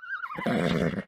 animalia_horse_idle.3.ogg